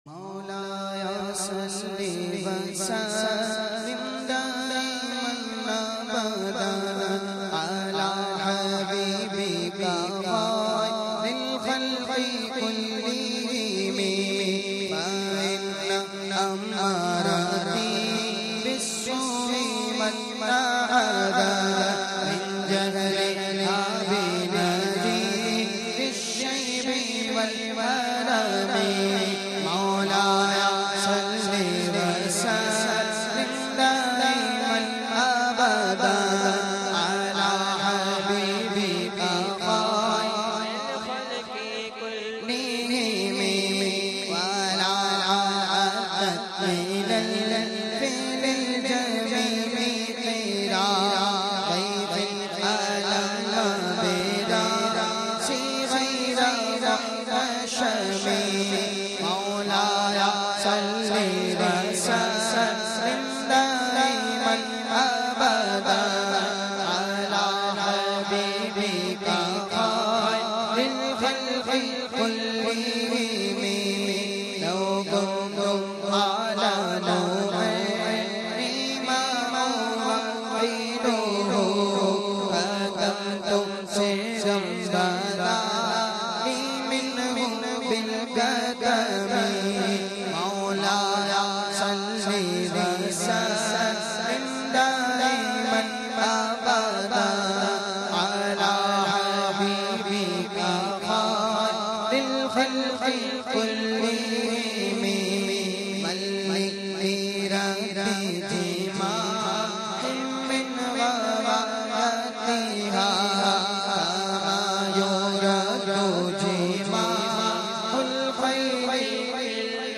recited by famous Naat Khawan